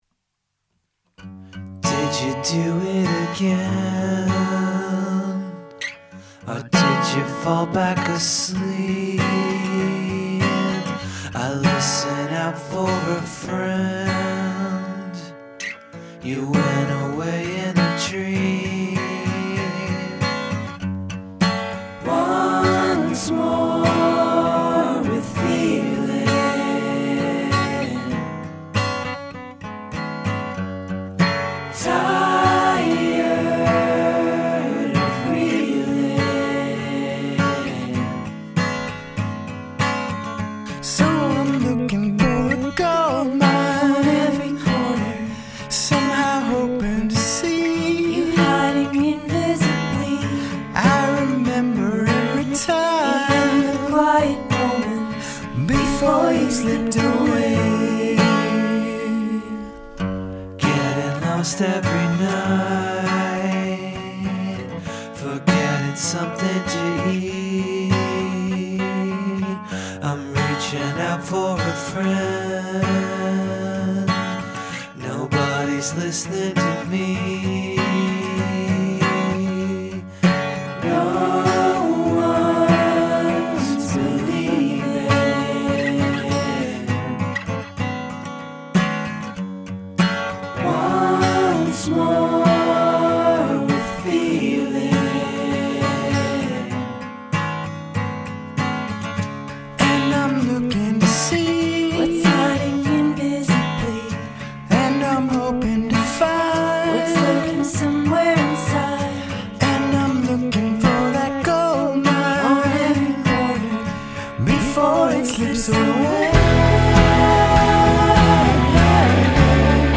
The bass sounds good, too.